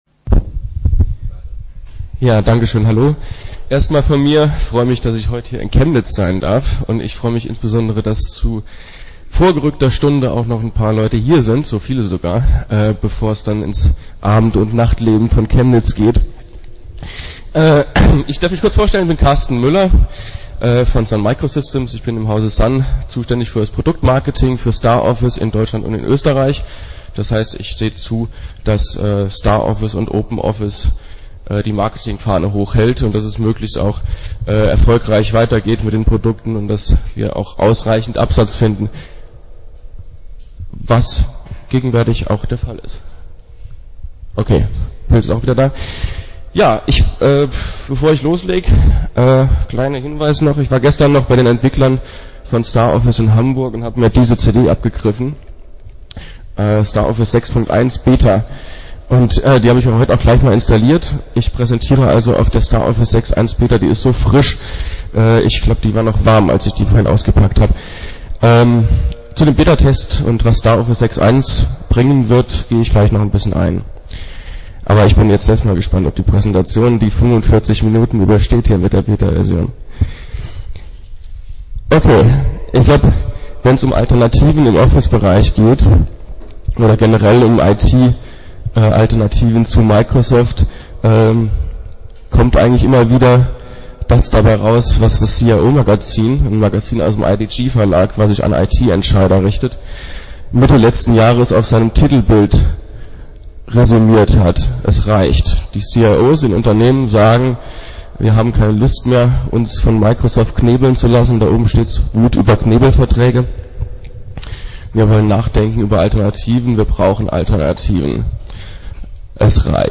Der 5. Chemnitzer Linux-Tag ist eine der größten Veranstaltungen seinder Art in Deutschland.
Vortragsmittschnitt